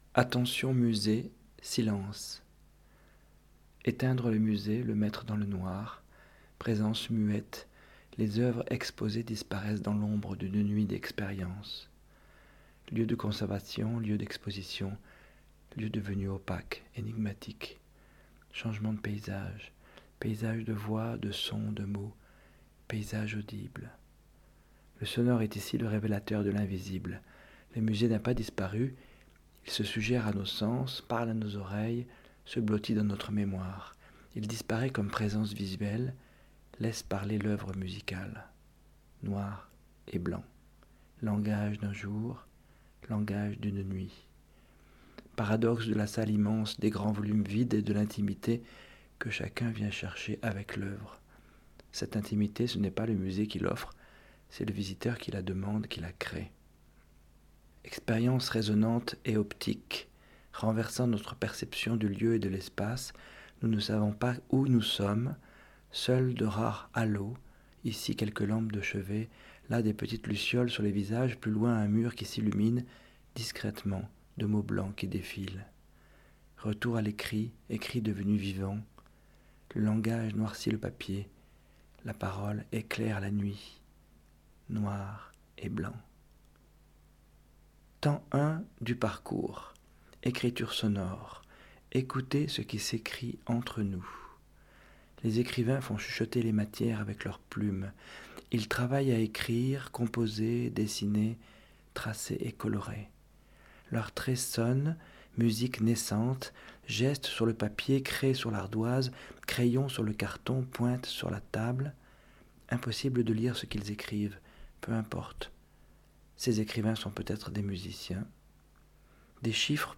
Car dans la pénombre, c’est bien le son qui surgit de ce cheminement. Soit celui d’un violon et d’un violoncelle, joués mais aussi frottés ou triturés, tout en accompagnant des dessinateurs. Soit celui d’un chœur anonyme, constitué de 140 voix, qui offre des bribes de phrases, dans une obscurité favorisant, à priori, la confidence.
Alors, la lecture de différents textes entremêlés revêt un caractère aliénant et interroge sur ce qu’est le langage. Cette performance devient un mantra hypnotique